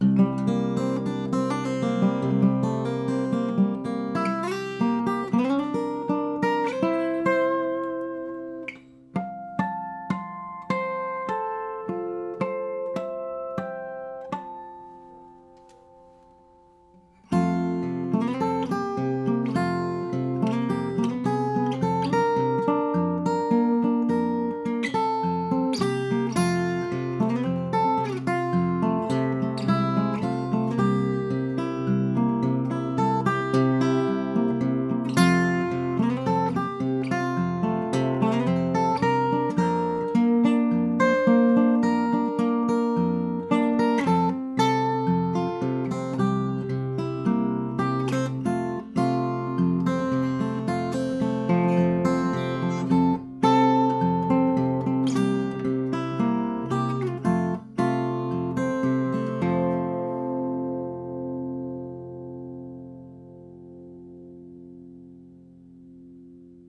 So here are the three clips I recorded in the video, with no EQ, no compression, no reverb, no processing of any kind except to match volume levels and trim ends.
Well my favorites, at least for acoustic guitar given these recordings, are L then J then K, can you provide the key?
My choice J then L and K Find J sharper , K more coloured with more mids.